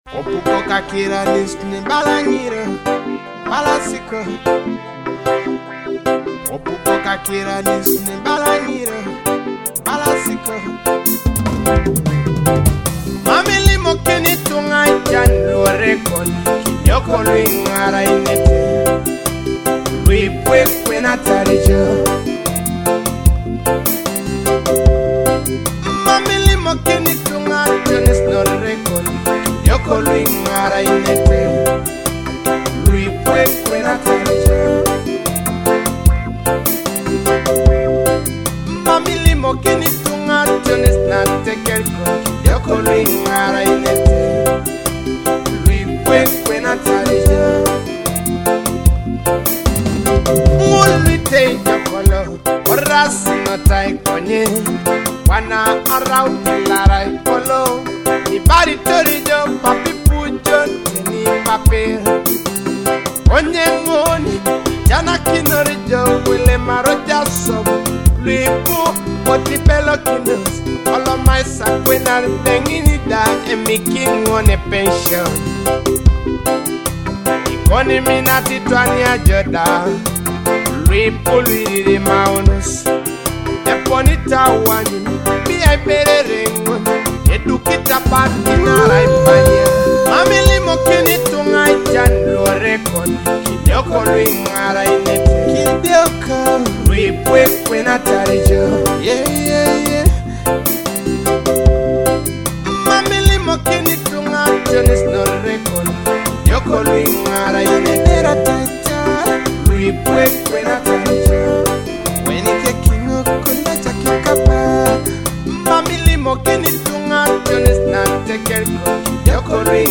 Enjoy authentic Eastern Ugandan sound in this powerful hit.
Teso hit
authentic Teso music experience